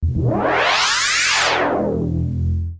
fxpTTE06011sweep.wav